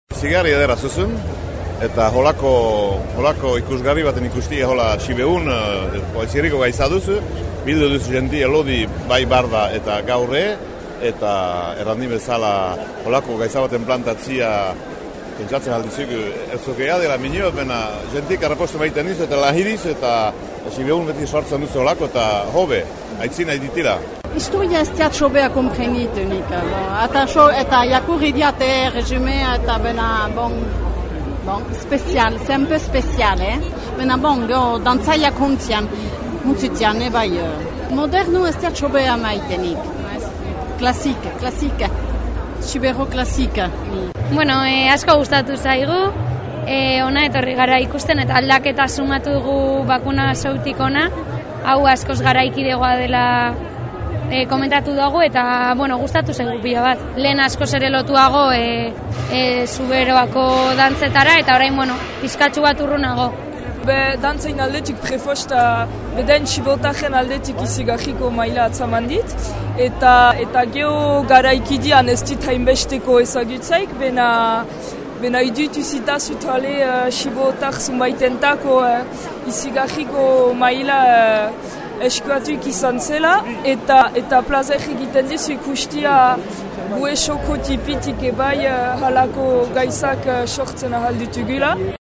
Uda batez ikusgarriak, xiberotik abiatü dü ützülia eta orotarat 10 aldiz emanik izanen da ; ondoko hitzordü zonbait , Hazparnen azaroaren 3, Garazin abentüaren 10 an eta urtarila 10 an Paueko zénith ean . lekukotasun zonbait ikusgarritik landa mikrotrotoir: